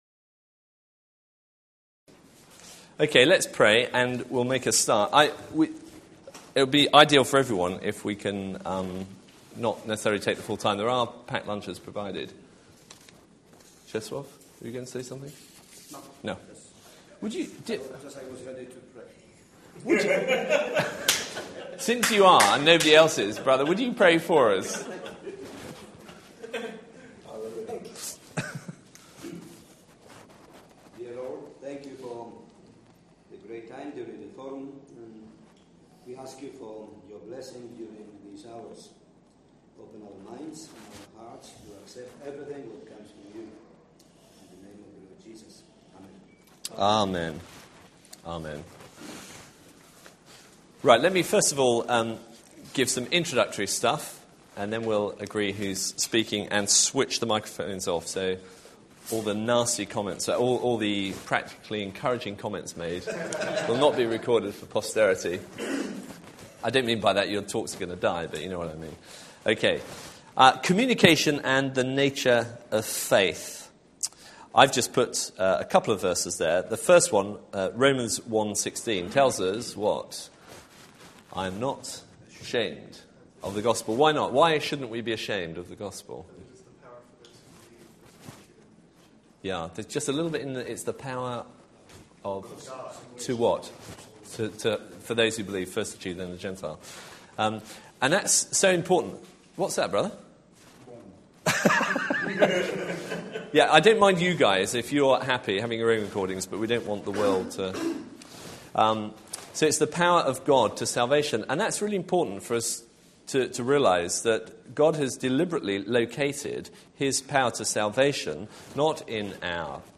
Event: ELF Workshop Topic: Evangelism › Persuasive Evangelism/Apologetics